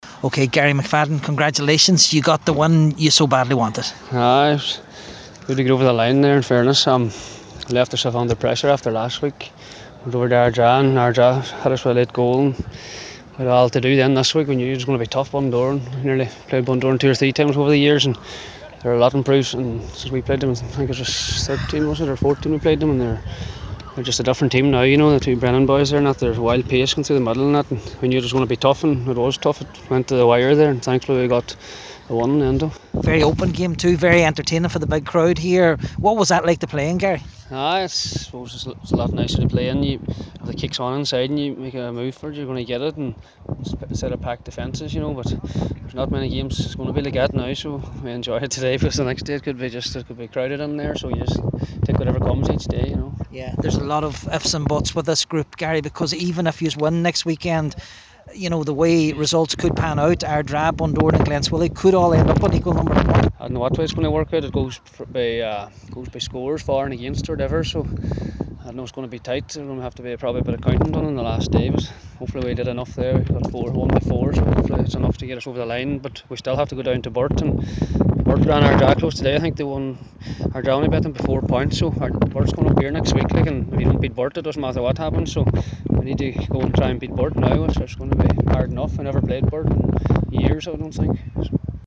After the match today